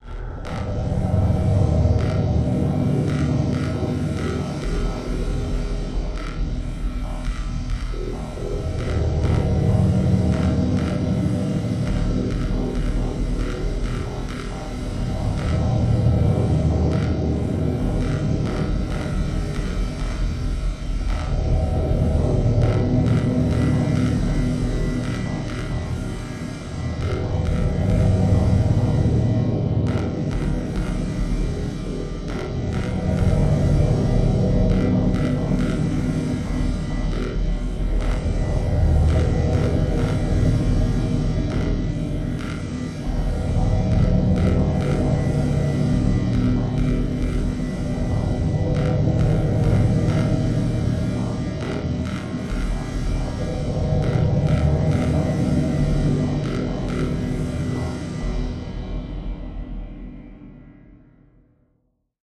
Voltage low pulsing electrical hum with electric spark accents